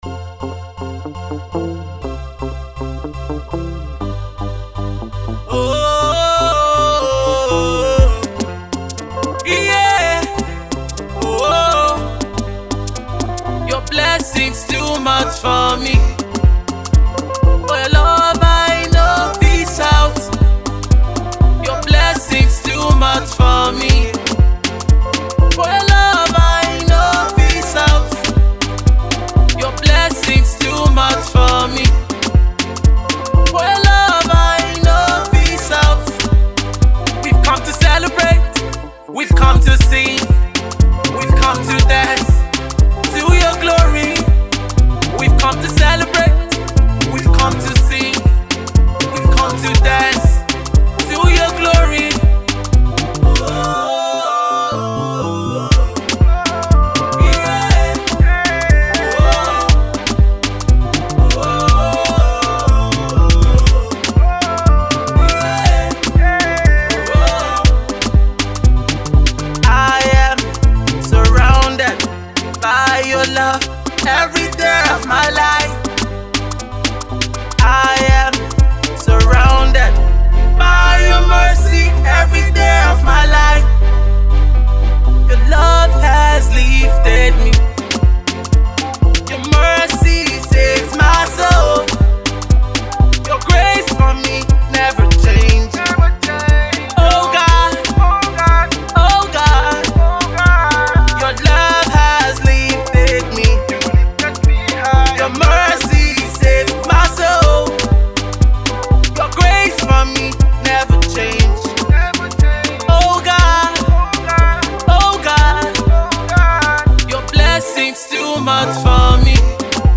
Nigerian gospel minister and prolific songwriter
a grooving song